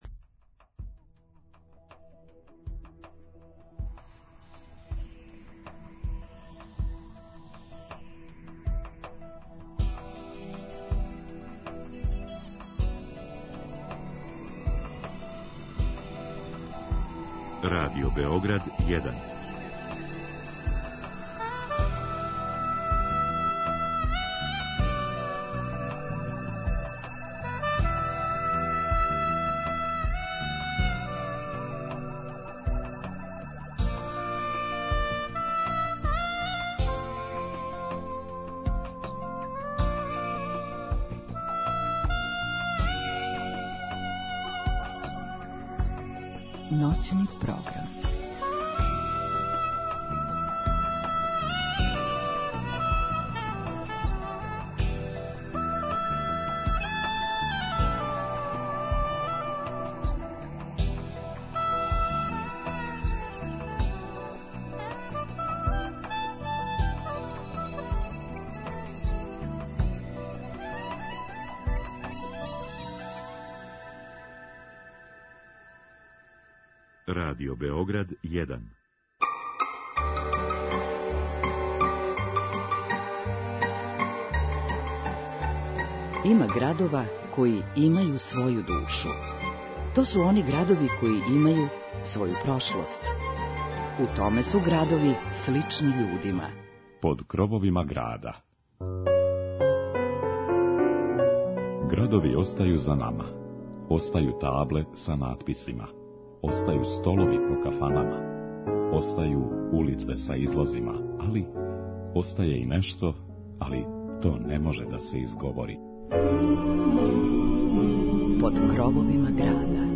У овој ноћи слушаћете звуке тамбурашких оркестара којима ћемо вас провести кроз Војводину.